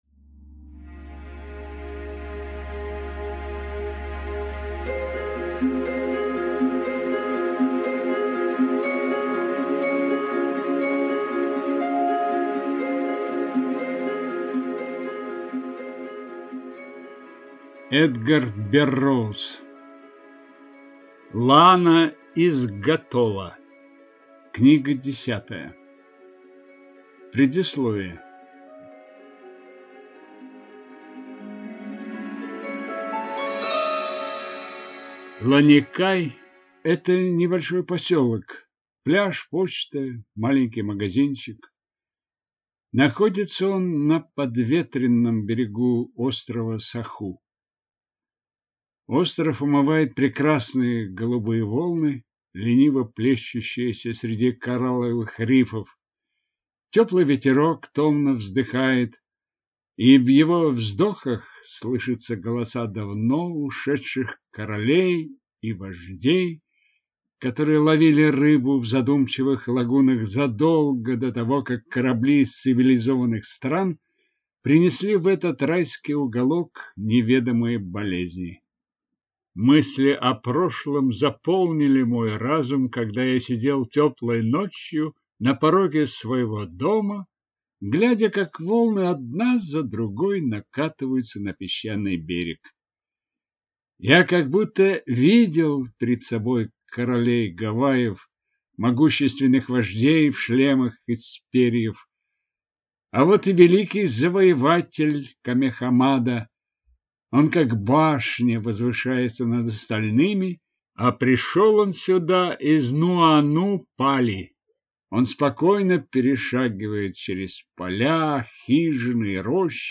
Аудиокнига Лана из Готала | Библиотека аудиокниг